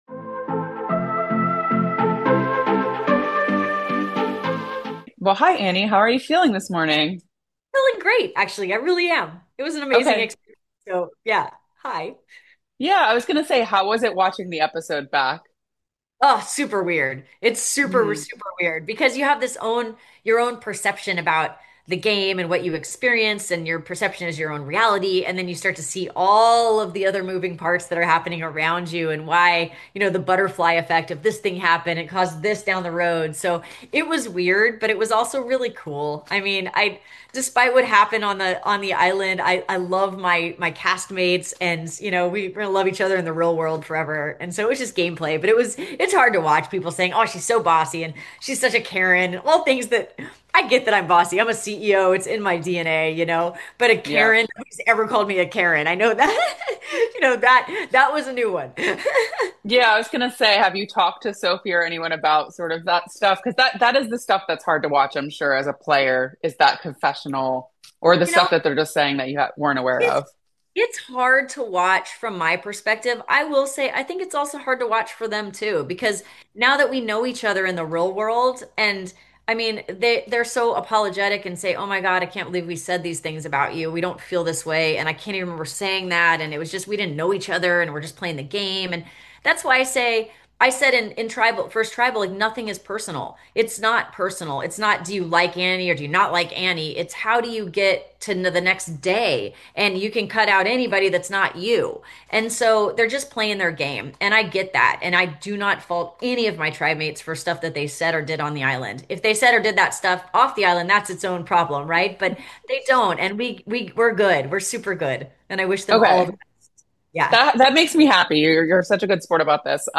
Survivor 49 Exit Interview